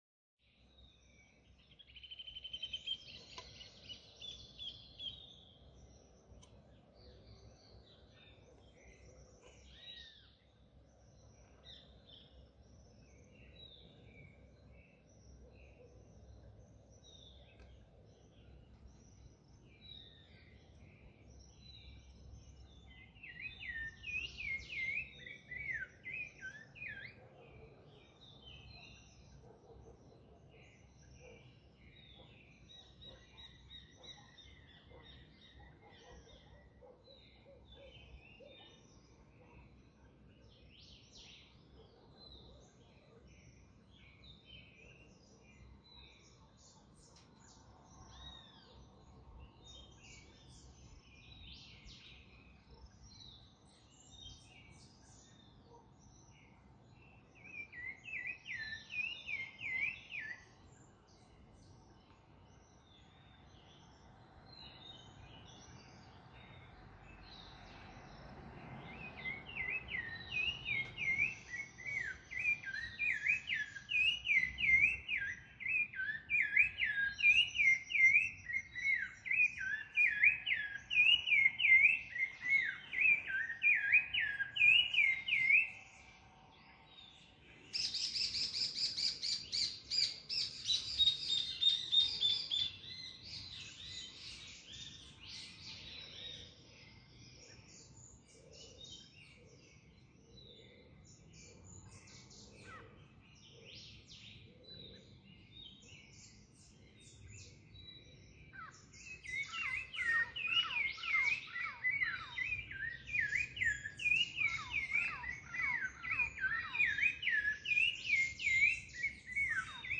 Birds-singing.mp3